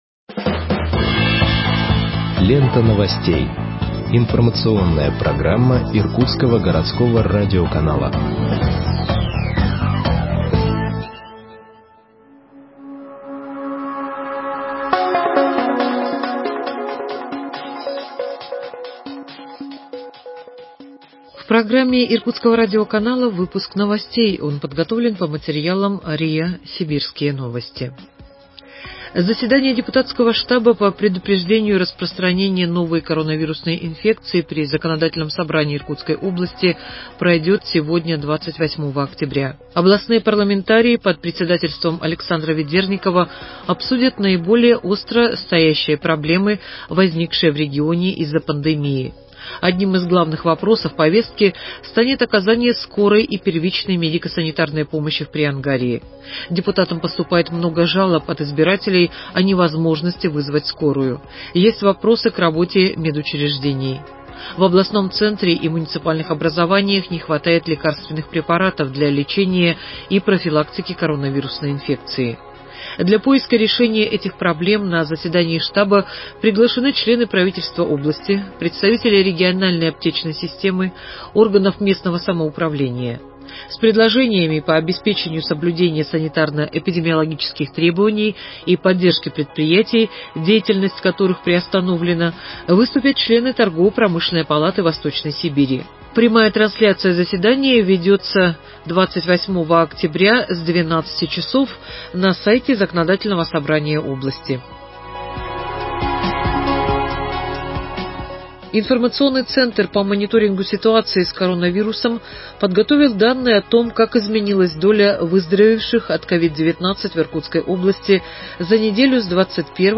Выпуск новостей в подкастах газеты Иркутск от 28.10.2020 № 2